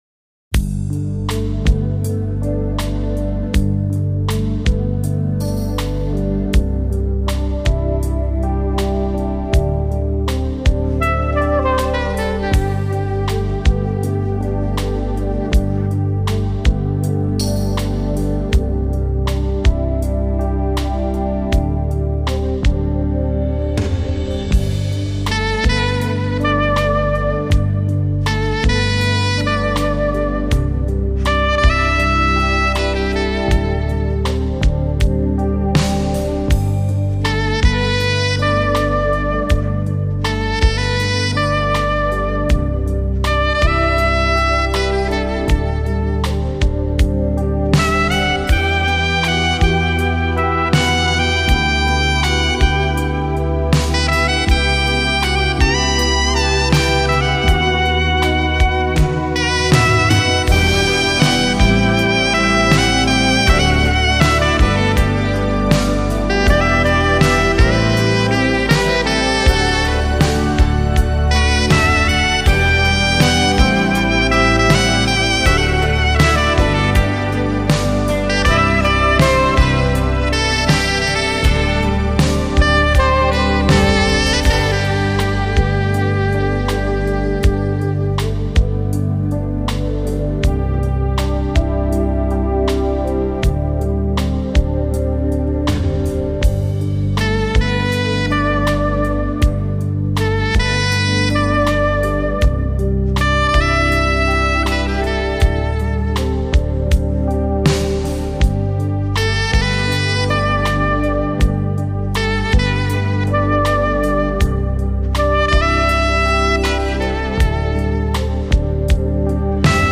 萨克斯